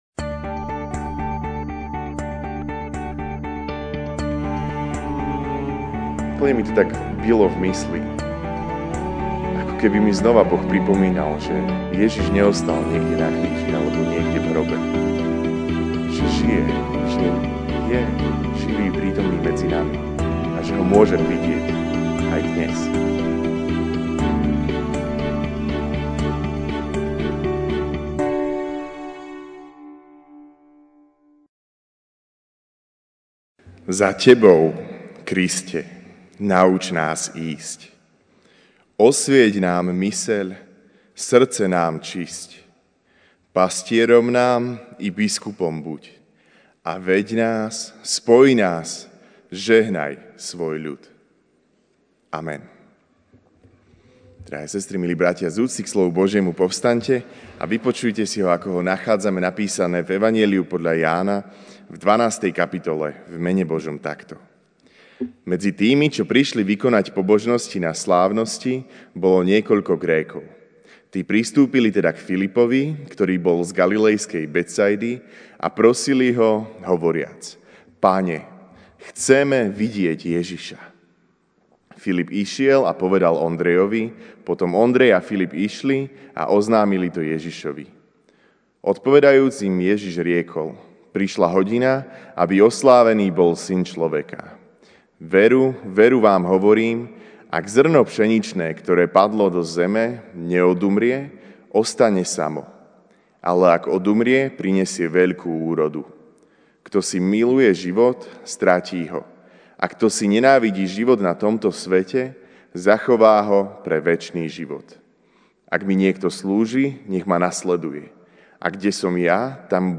MP3 SUBSCRIBE on iTunes(Podcast) Notes Sermons in this Series Večerná kázeň: Videl si už môjho Syna?